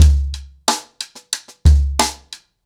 Wireless-90BPM.7.wav